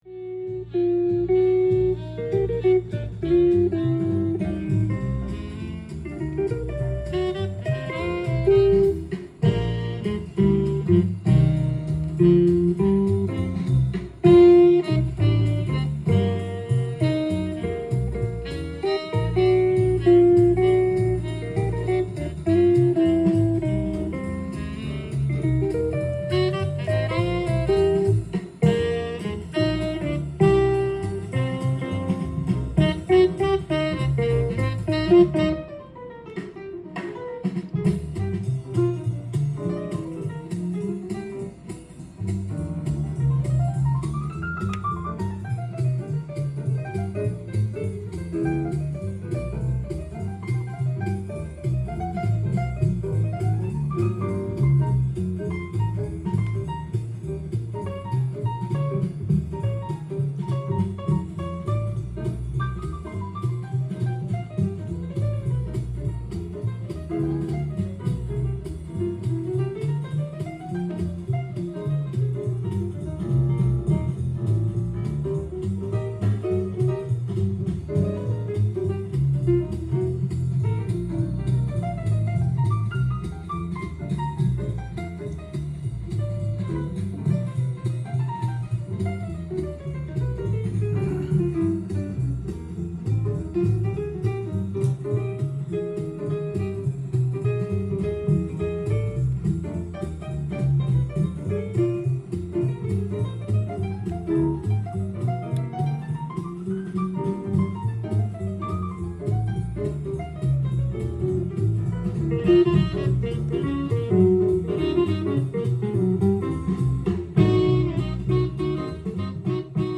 ジャンル：JAZZ-ALL
店頭で録音した音源の為、多少の外部音や音質の悪さはございますが、サンプルとしてご視聴ください。
ジョニースミスやタルファーロウ辺りにも近い雰囲気で、白人らしいクールなギタースタイル。